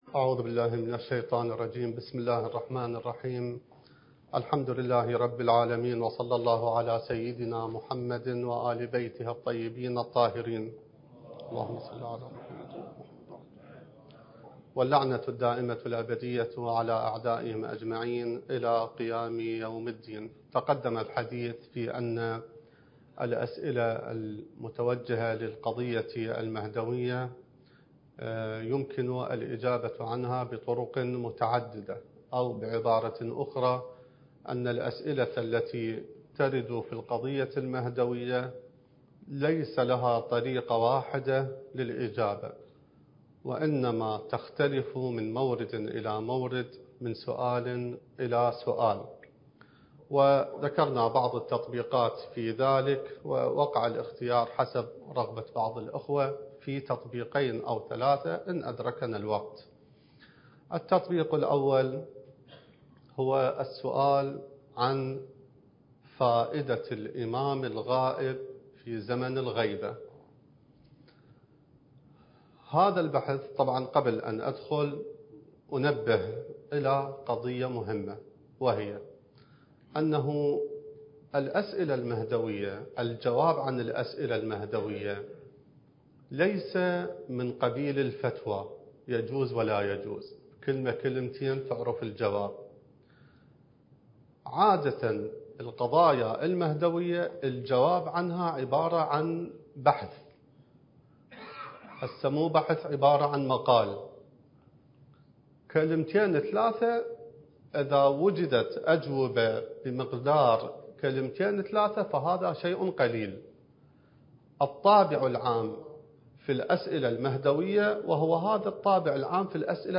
الدورة المهدوية الأولى المكثفة (المحاضرة الثالثة والثلاثون)